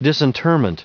Prononciation du mot disinterment en anglais (fichier audio)
Prononciation du mot : disinterment